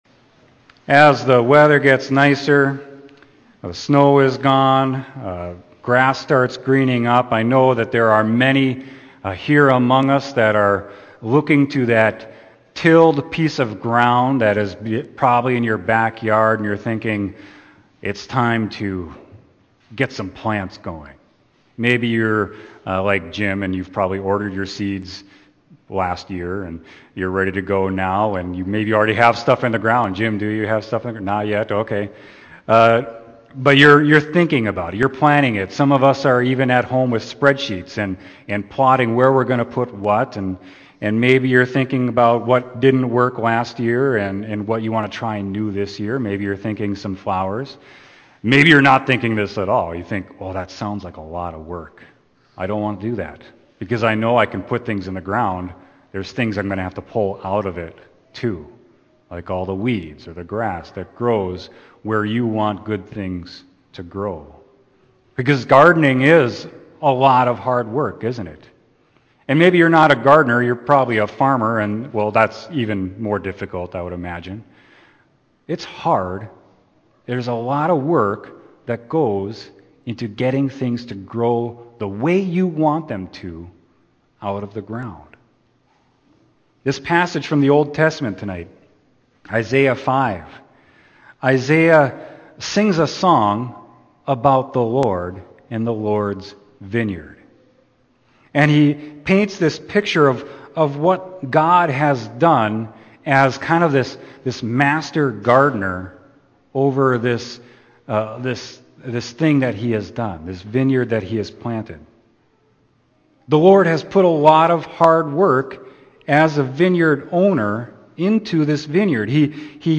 Sermon: John 15.1-17